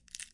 描述：骨头紧缩。
Tag: crunh